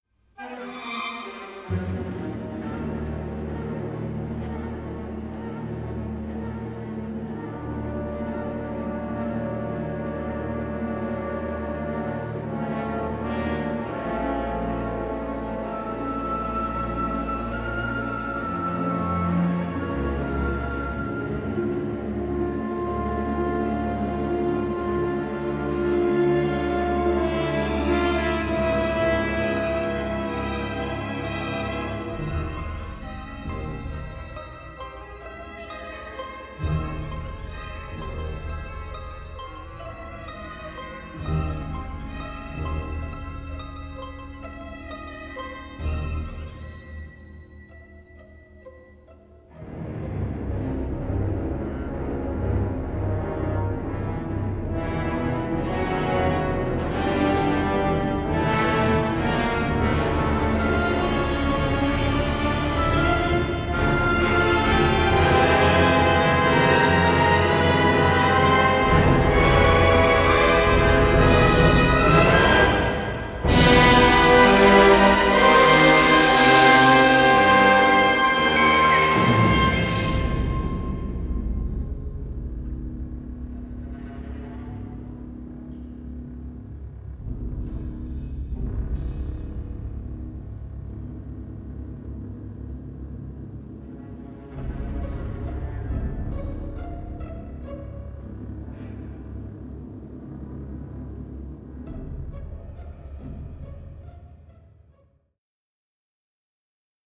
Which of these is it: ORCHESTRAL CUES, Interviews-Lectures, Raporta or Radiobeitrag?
ORCHESTRAL CUES